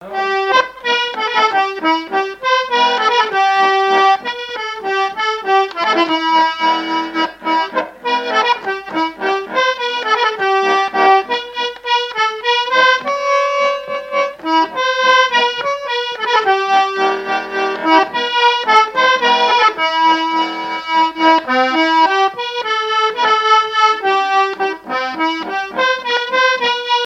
Fonction d'après l'analyste danse : valse
Genre strophique
Répertoire et souvenir des musiciens locaux
Catégorie Pièce musicale inédite